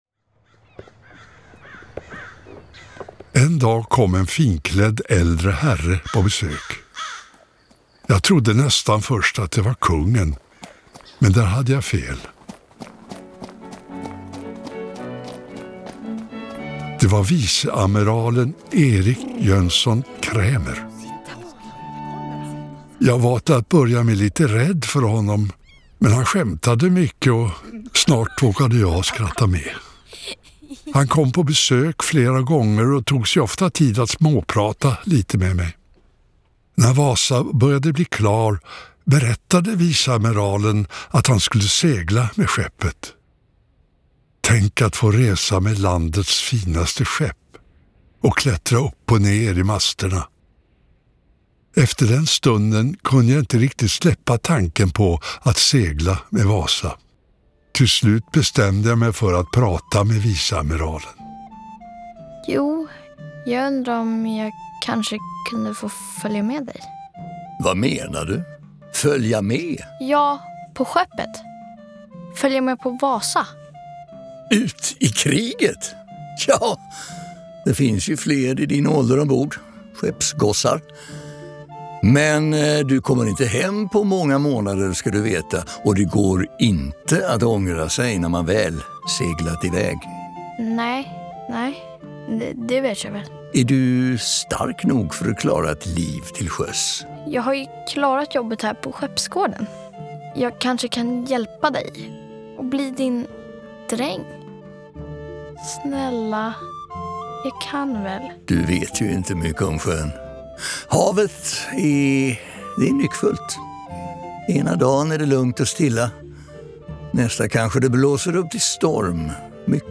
Museets audioguide för barn och familj 'Pojken och skeppet: en resa i det förflutna' handlar om Olof som lever i Stockholm i början av 1600-talet.